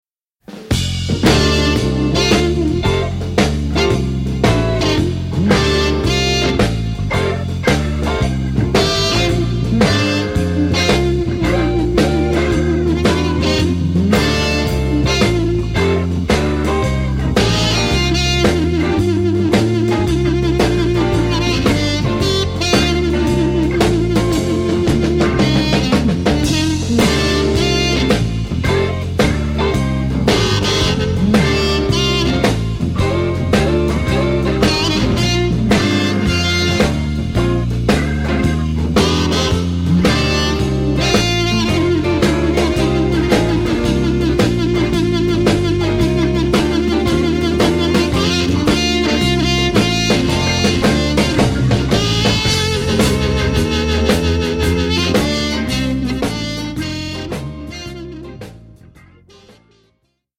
I like playing in f.